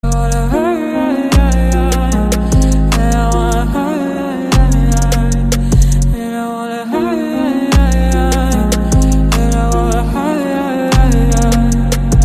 Cartman crying